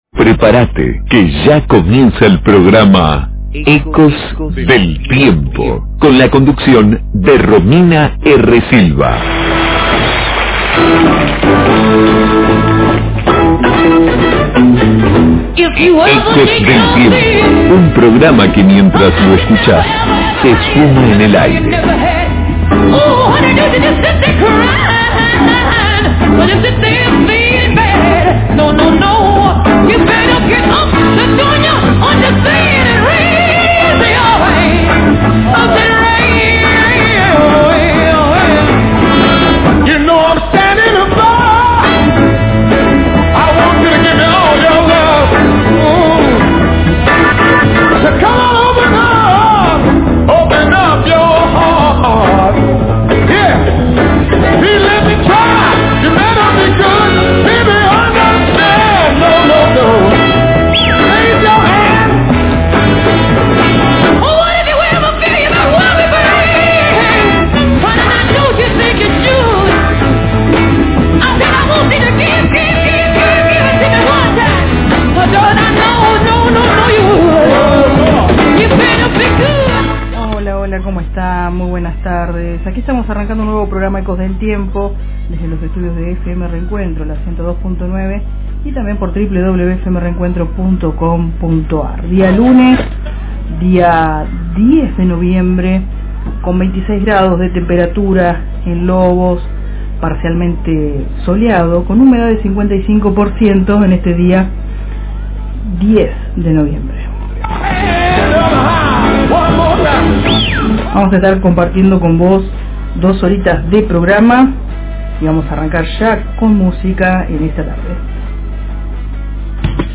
✨ Entrevistas